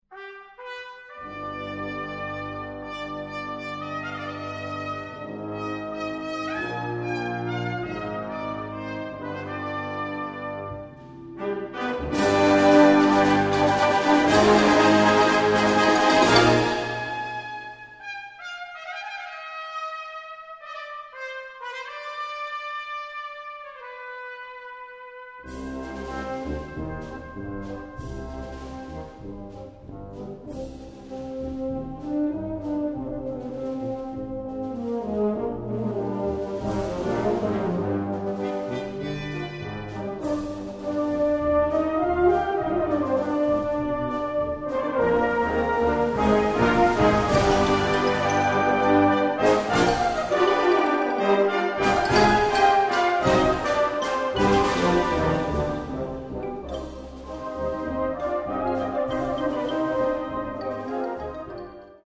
The result is this beautiful paso-doble.